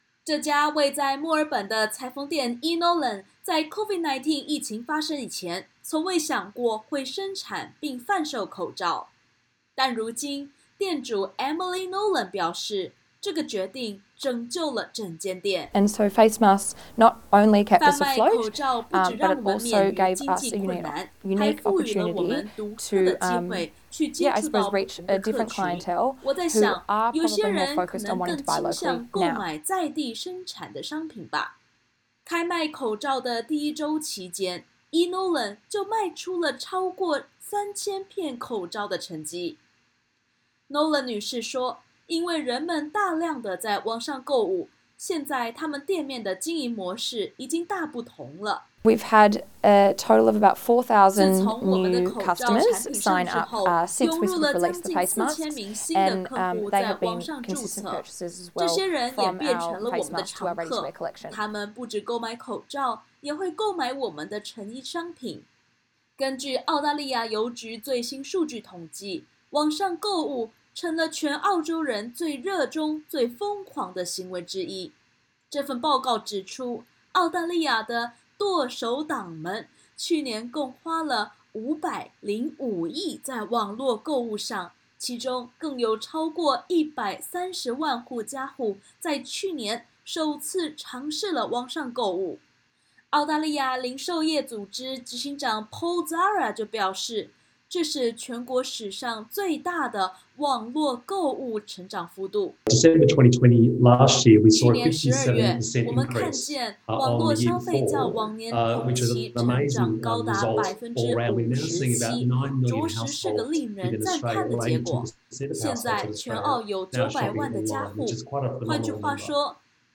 COVID-19疫情困在家没事做只好疯狂消费？这场疫情让全澳洲的“剁手党”持续扩大中，去年澳洲网上购物市场，足足有超过500亿的消费金额。点击首图收听完整音频报导。